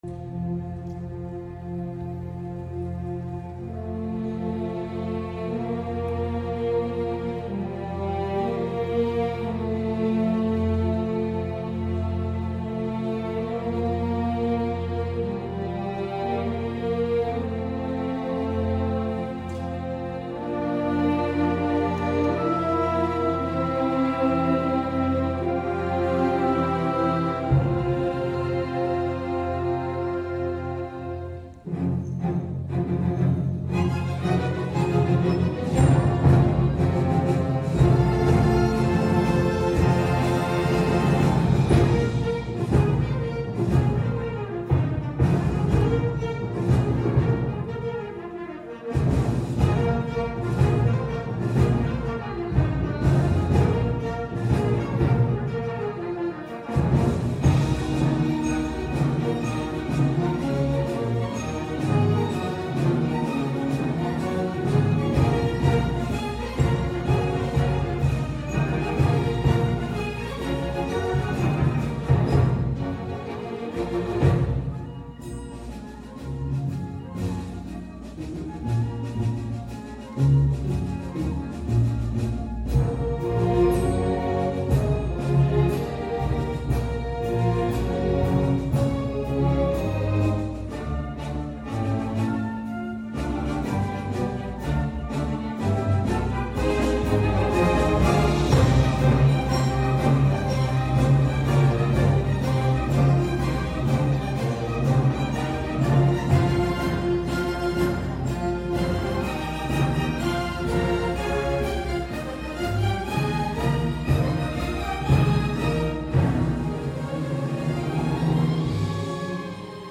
Orchestrated